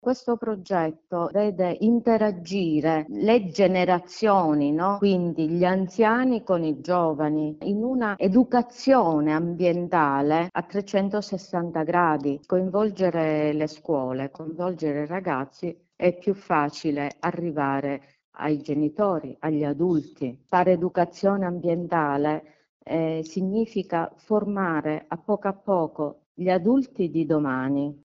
Nella provincia di Palermo, giovani e anziani uniti nel riciclo e nel riuso. Grazie a un impianto di trasformazione della plastica infatti, bottiglie e flaconi diventano elementi di arredo urbano. Ai nostri microfoni